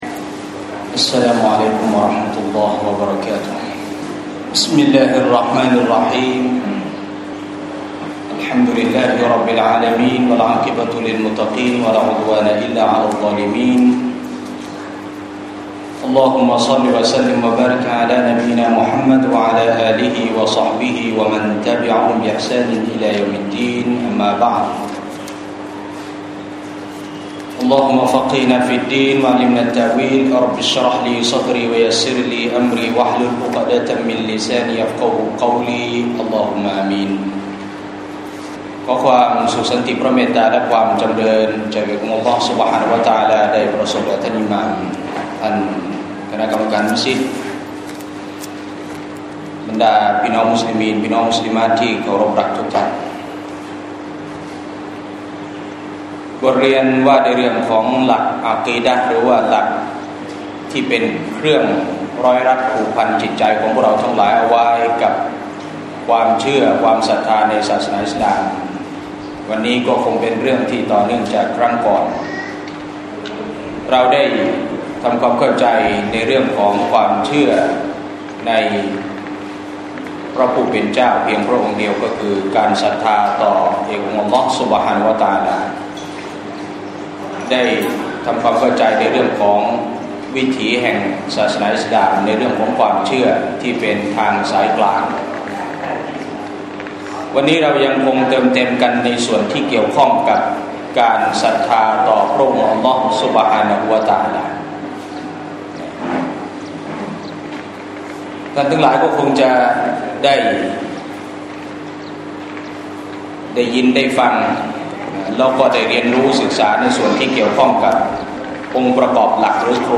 มัสยิดกลางจังหวัดชลบุรี